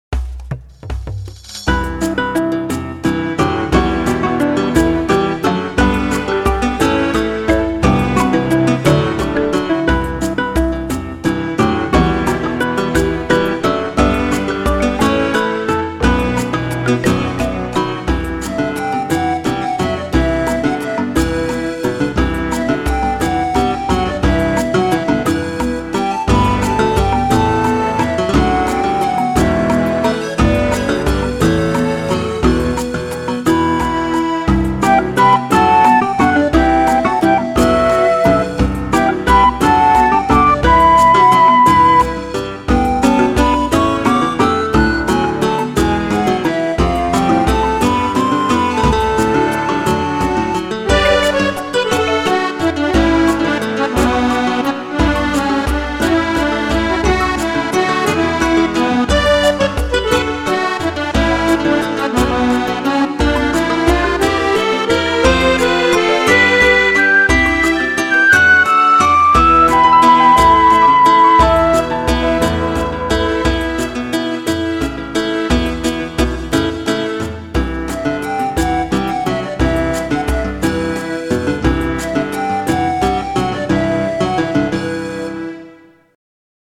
夏だよね民族音楽だよね、ってことで民族転調を駆使した曲にチャレンジしてみたよ。タイトルは「砂漠の村リトット」最近読んだスレまとめの影響です。
ついでにメロディは途中で飽きて終わった壊れたピアノの曲から再利用だし。
どうでもいいけど、砂漠っていうより山岳だなあ。